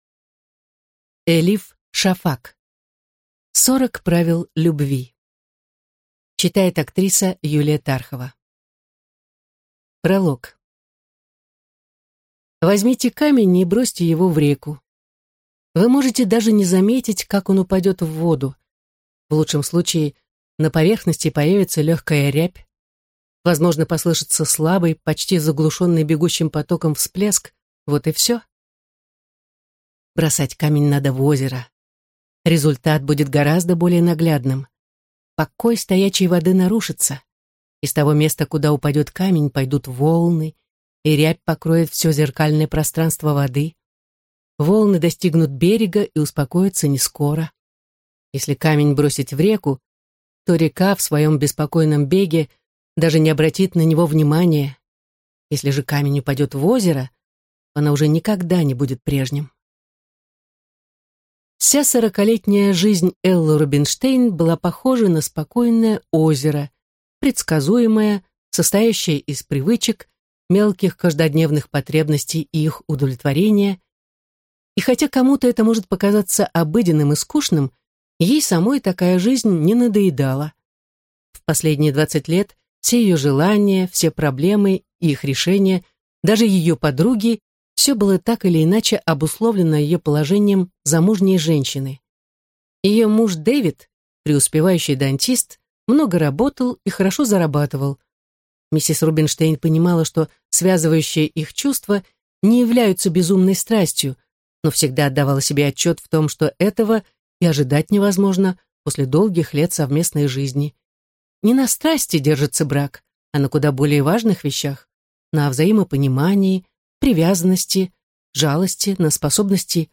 Аудиокнига Сорок правил любви | Библиотека аудиокниг